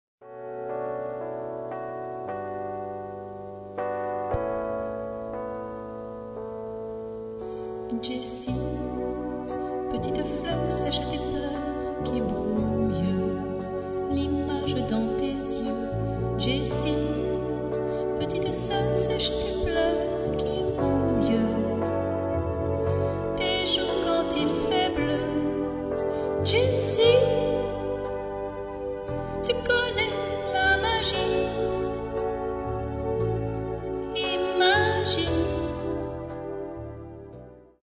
SPECTACLES POUR ENFANTS : contes musicaux